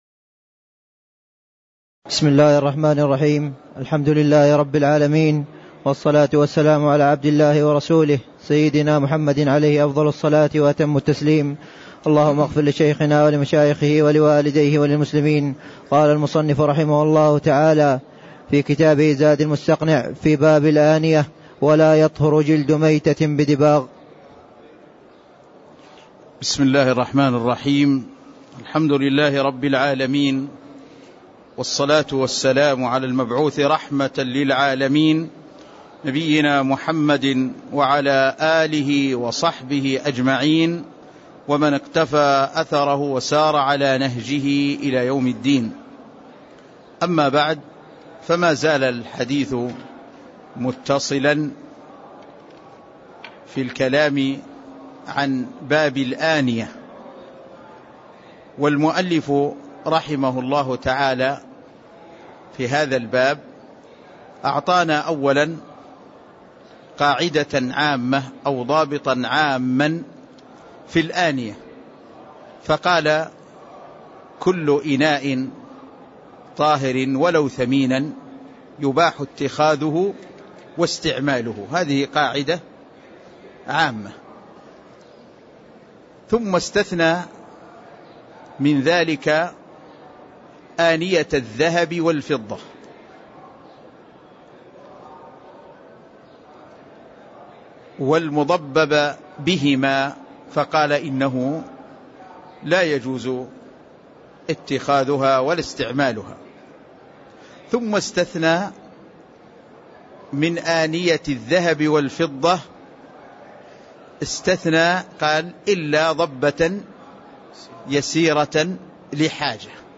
تاريخ النشر ١٨ ربيع الثاني ١٤٣٥ هـ المكان: المسجد النبوي الشيخ